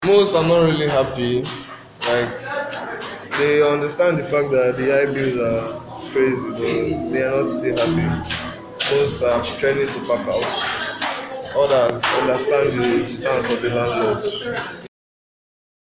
In an interview with Boss Radio correspondent, a